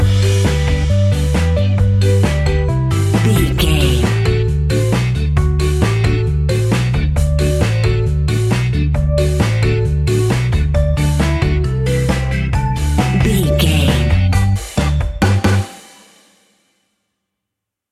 Ionian/Major
laid back
chilled
drums
skank guitar
hammond organ
horns